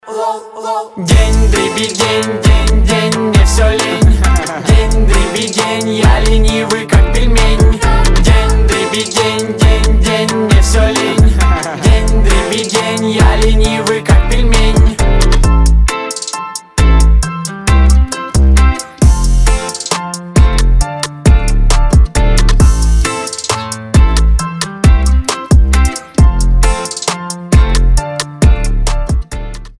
• Качество: 320, Stereo
смешные